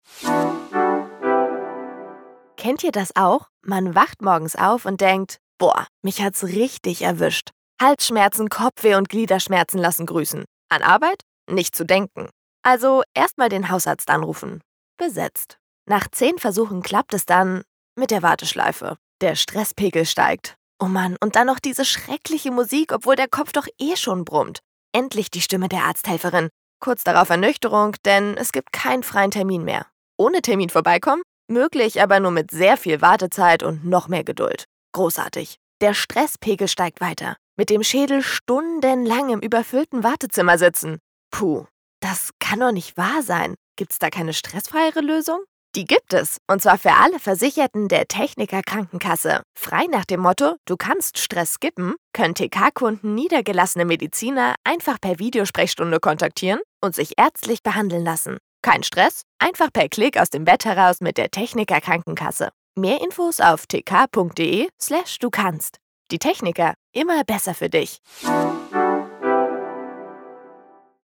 hell, fein, zart
Jung (18-30)
Eigene Sprecherkabine
Narrative